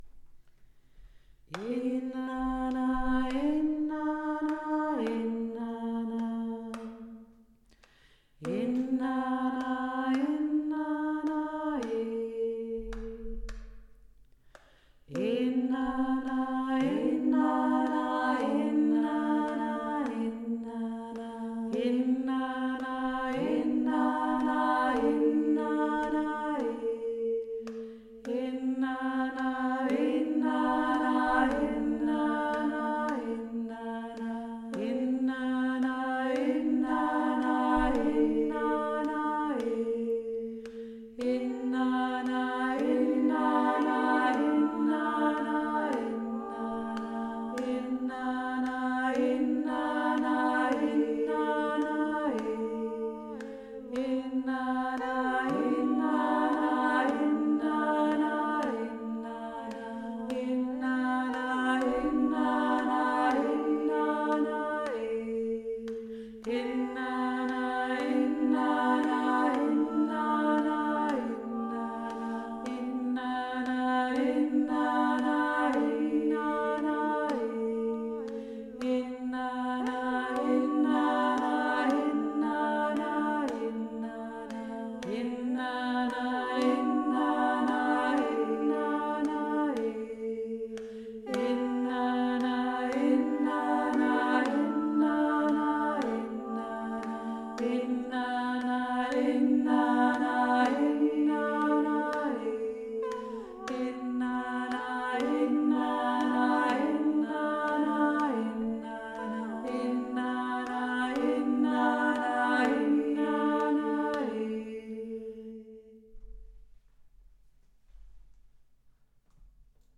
Nur die Grundstimmen (so könnt ihr dazu singen)